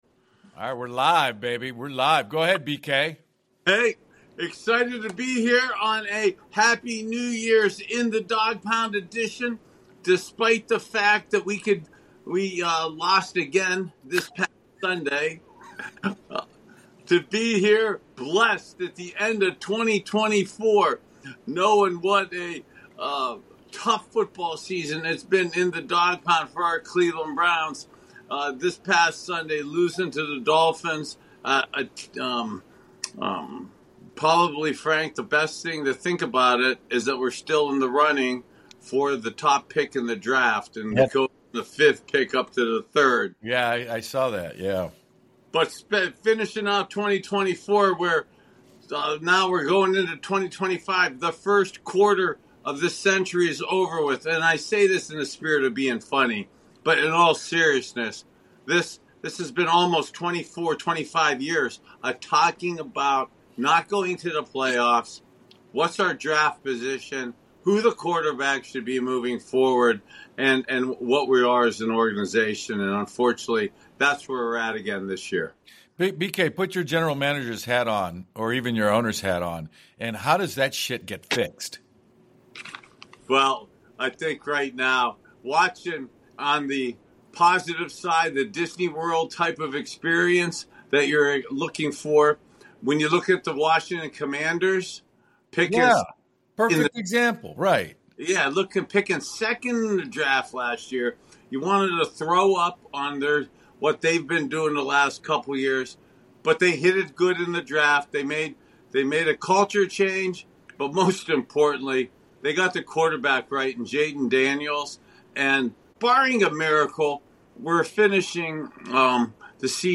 Join the dawgs Bernie Kosar, Eric Metcalf & Frank Stams every week "In the Dawg Pound". Each week we will share locker room stories, give you our perspective on the team’s performance, serve up endless smack talk and tell you what and who we are betting on next week. We will also have surprise guests in the pound to see if they have what it takes to hang with the Big Dawgs.